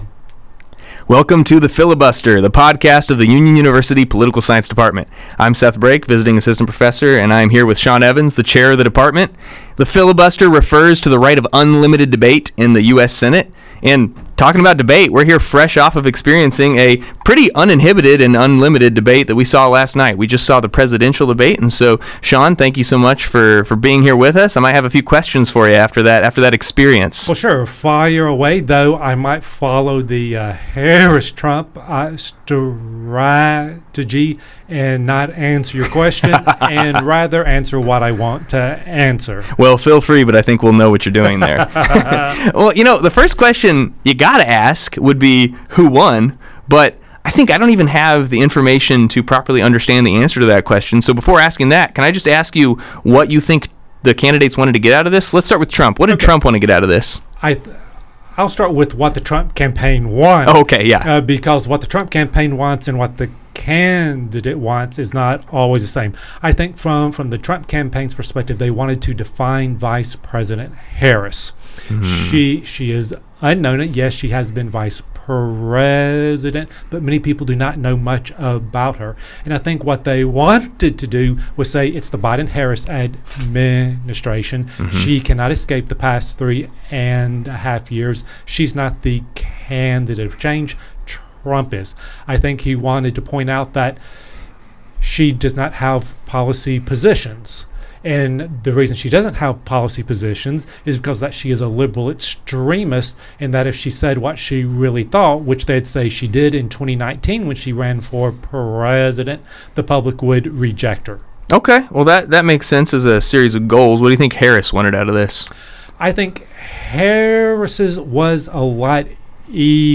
The faculty discuss the politics and debate strategies of former President Donald Trump and Vice President Kamala Harris in the first presidential debate. The episode ends with a discussion of how Christians should think about the debate and election.